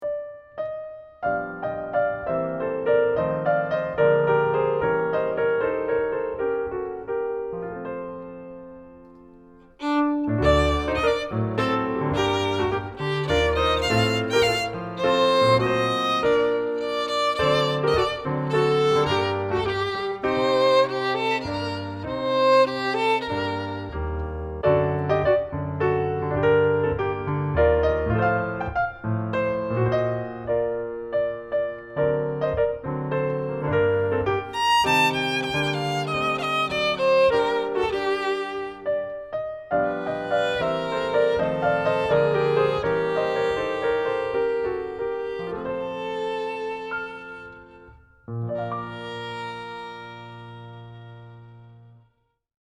violin - cello - viola